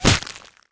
bullet_impact_ice_07.wav